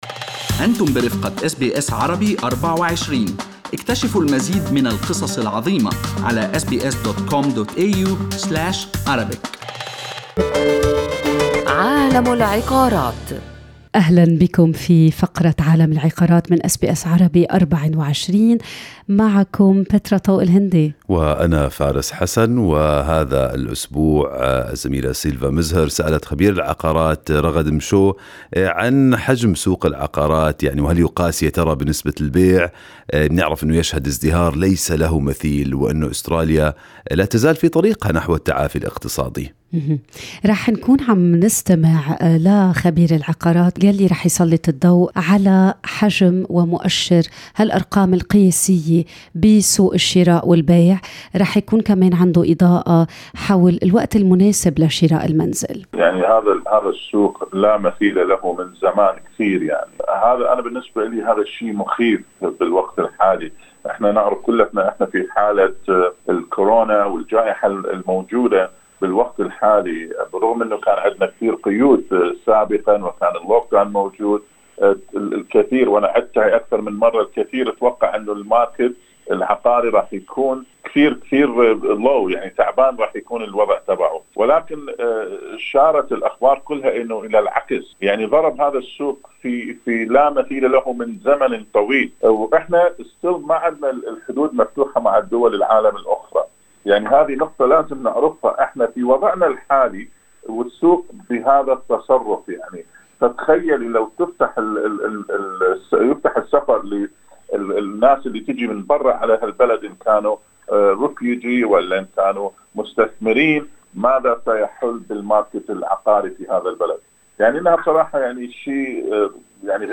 وأشار تقريرٌ إلى أن زيادة الطّلب على البيع لا تقتصر فقط على المدن الكبرى كسيدني وملبورن، إذ إنّ مؤشّر البيع في المزارع البعيدة يرتفع أيضًا. لمعرفة المزيد عمّا جرى بحثه، يمكنك الاستماع إلى كامل المقابلة في الملّف الصوتيّ أعلاه.